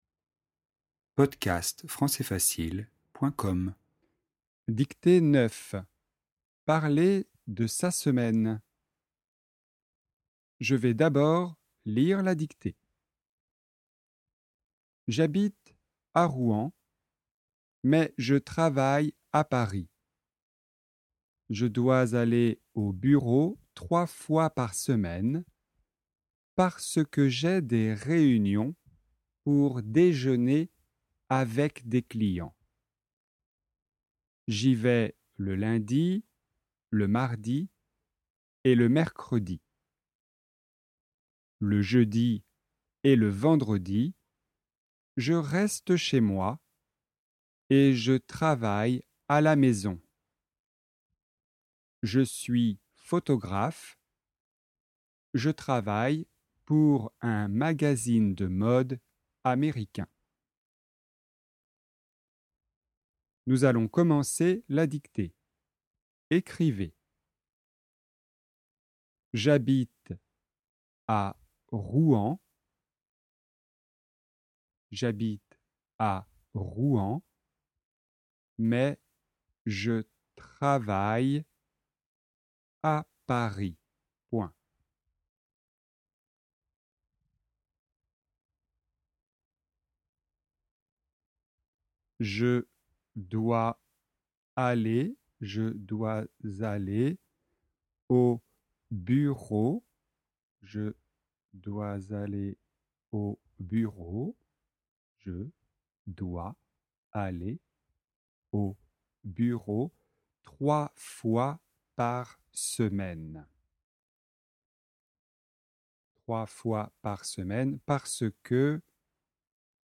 Dictée 9 : parler de sa semaine